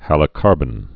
(hălə-kärbən)